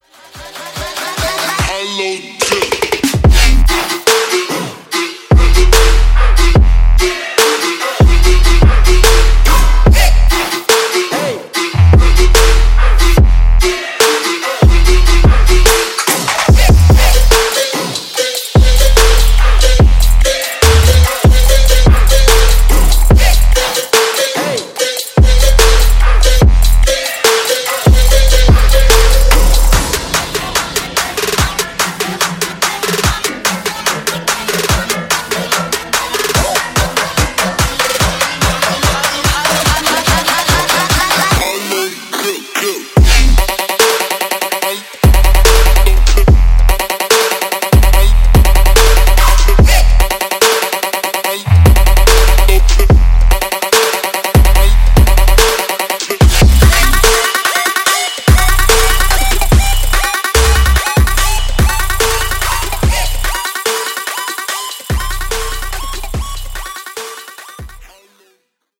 • Качество: 320, Stereo
громкие
жесткие
электронная музыка
мощные басы
Trap
качающие